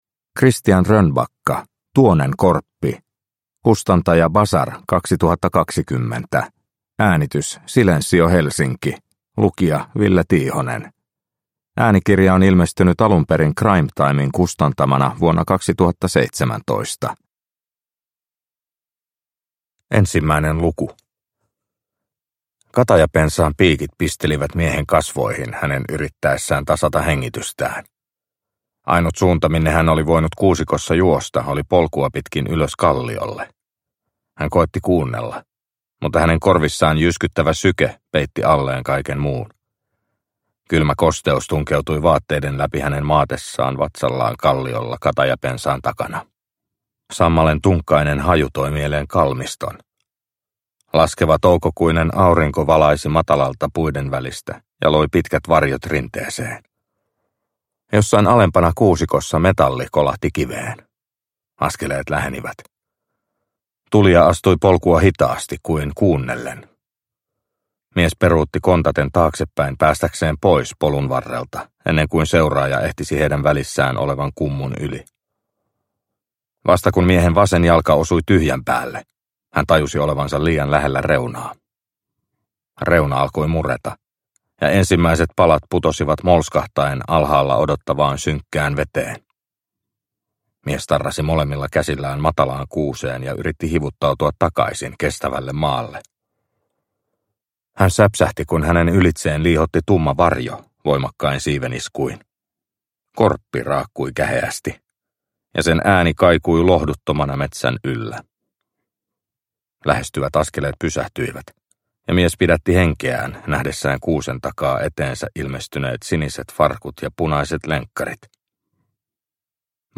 Tuonen korppi – Ljudbok – Laddas ner